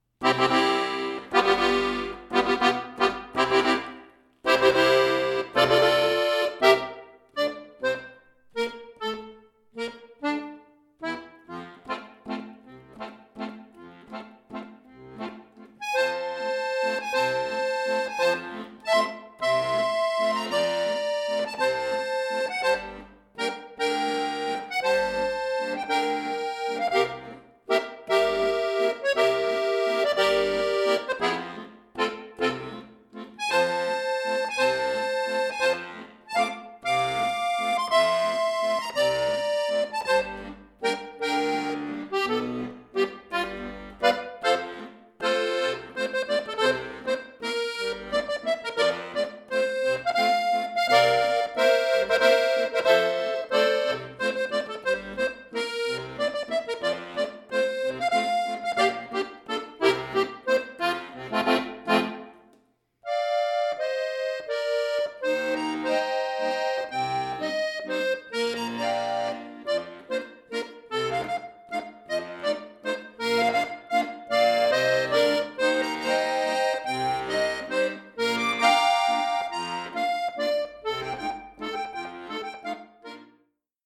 Walzer
bearbeitet und gekürzt für Akkordeon solo
Klassisch, Walzer